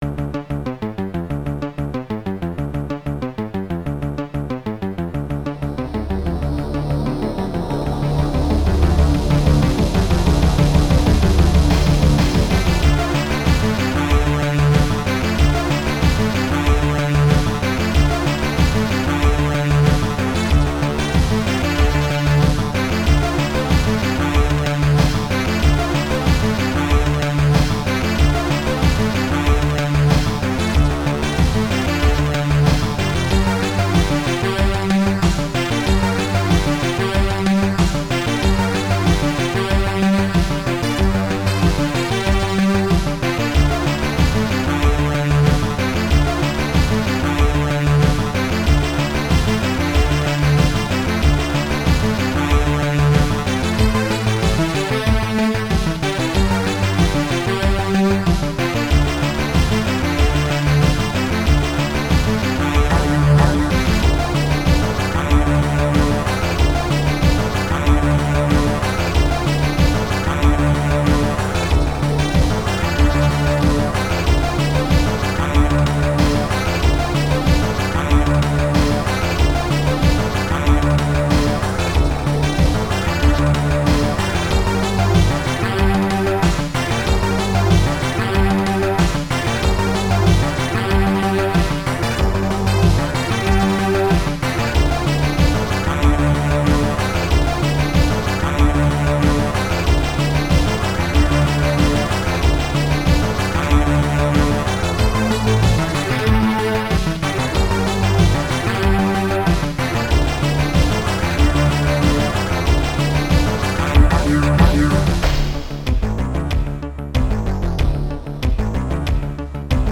Synth
SoundMon module